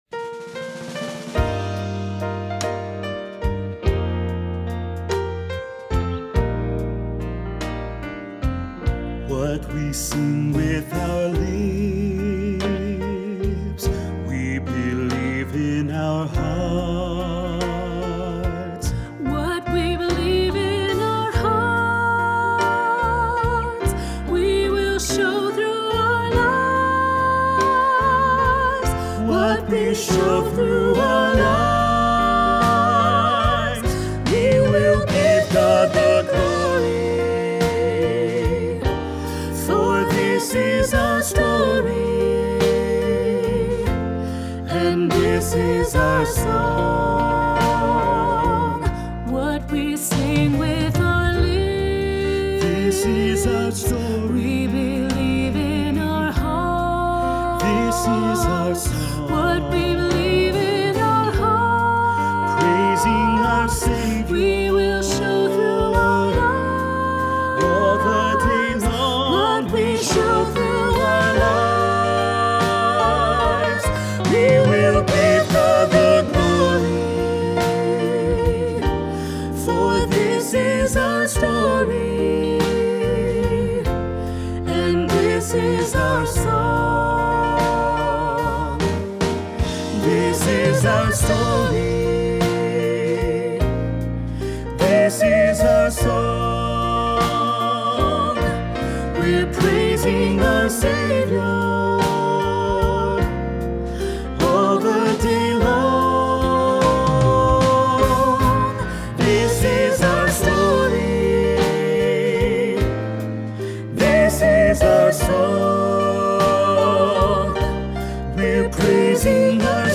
Voicing: Medium Voice